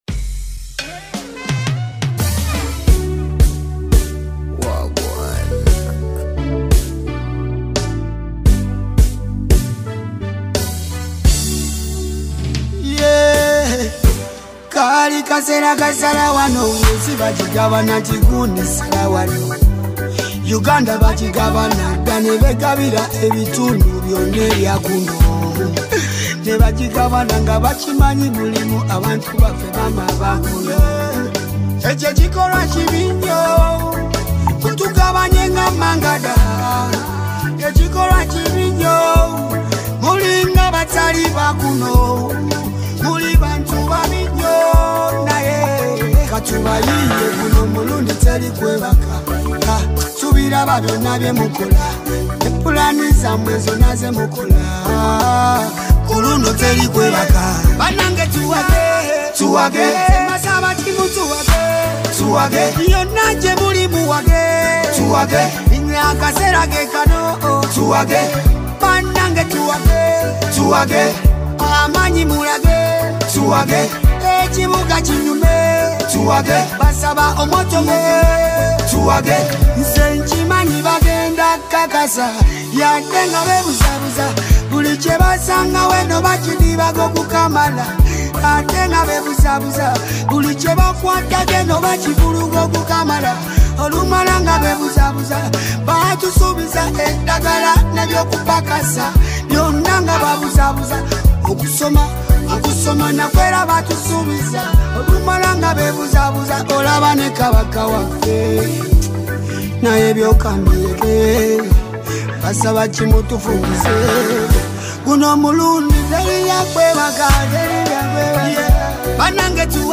Afro Beat singer